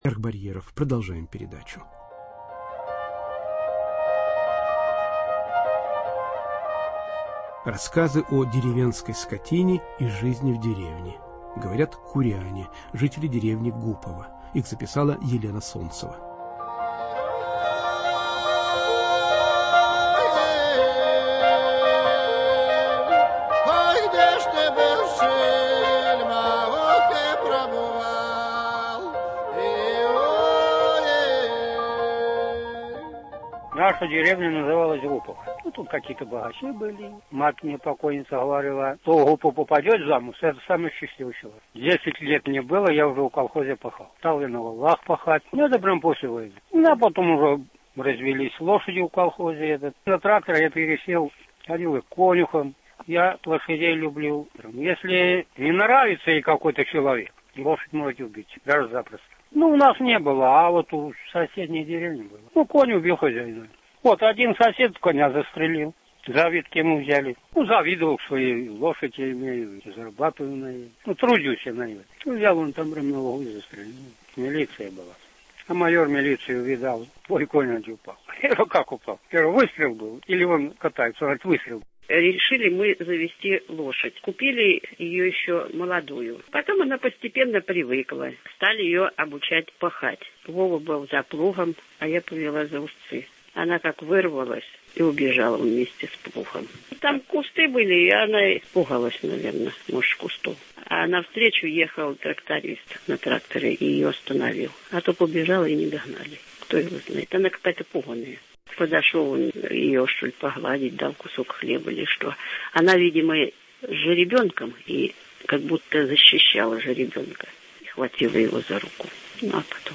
Жители деревни Гупово (Курская область) рассказывают о том, как они живут и выживают в российской глубинке